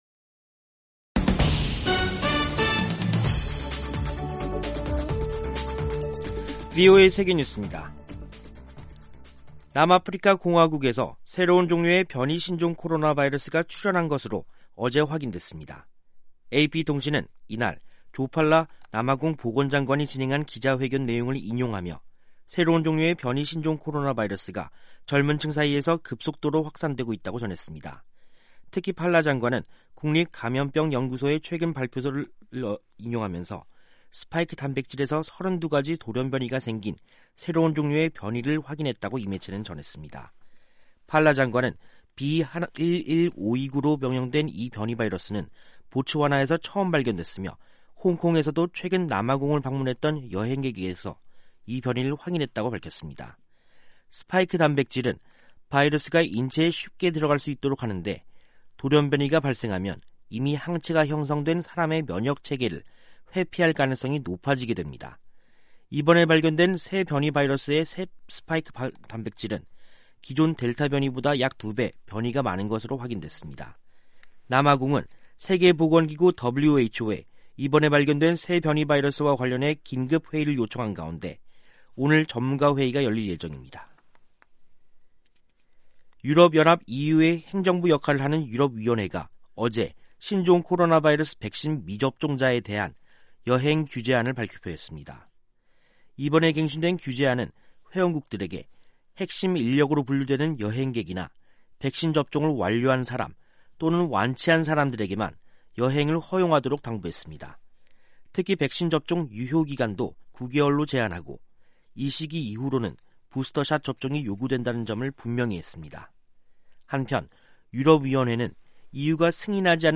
세계 뉴스와 함께 미국의 모든 것을 소개하는 '생방송 여기는 워싱턴입니다', 2021년 11월 26일 저녁 방송입니다. '지구촌 오늘'에서는 최근 아프리카에서 발견된 새로운 변이 바이러스에 전 세계가 또다시 초비상이 걸린 소식, '아메리카 나우'에서는 조 바이든 대통령이 추수감사절을 맞아 해안경비대를 찾아 대원들을 격려하는 등 미국이 다시 정상으로 돌아왔다고 강조한 소식 전해드립니다.